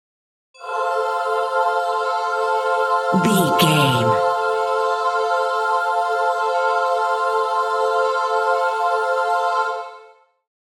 Angels Choir
Sound Effects
Atonal
dreamy
bright
calm